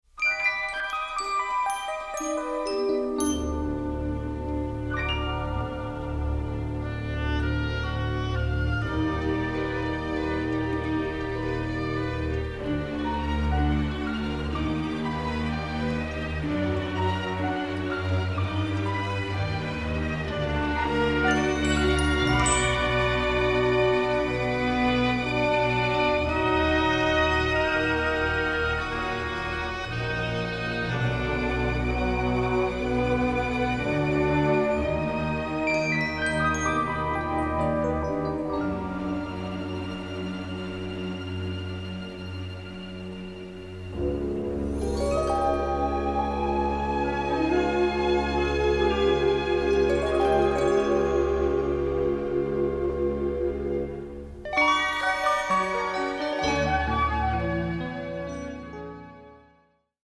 robust orchestral score